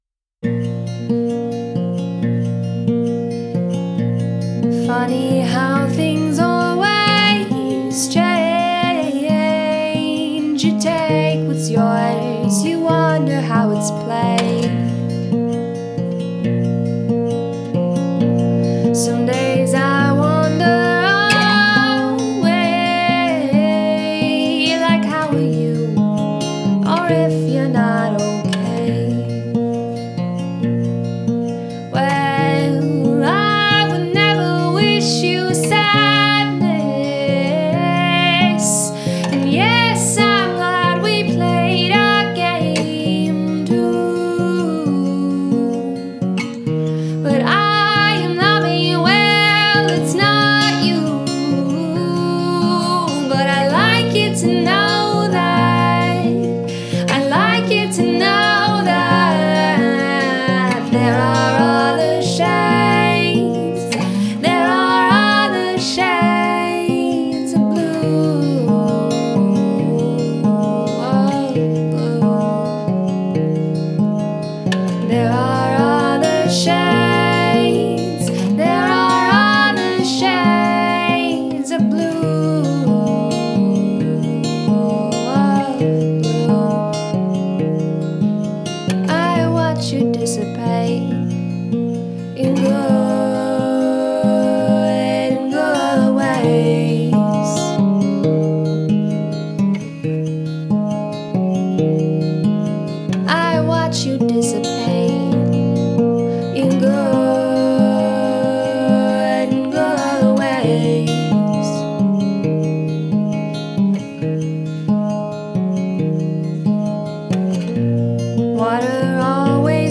"indie folk"